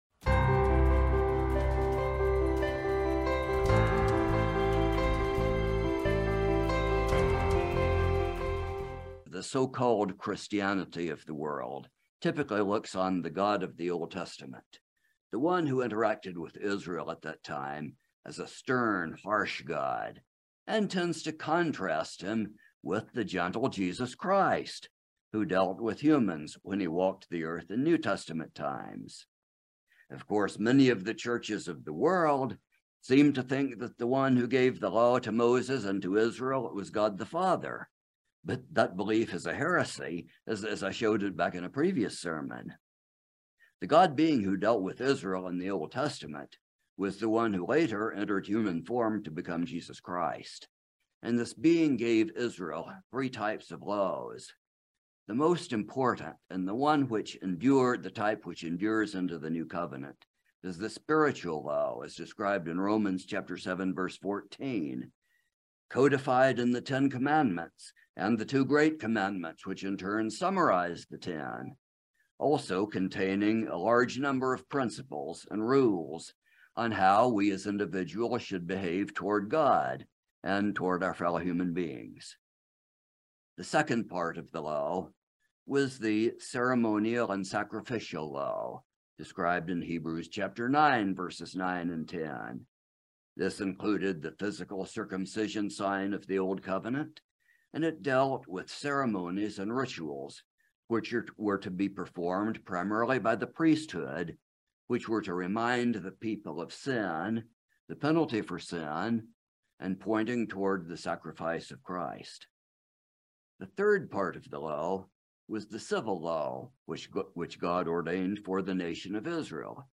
The mainstream Christian denominations tend to view the God of the Old Testament as a stern, harsh God as contrasted with a kind and gentle Jesus Christ. This sermon explains that the same One we know as Jesus Christ was the One who interacted with the Prophets and Patriarchs during the Old Testament era.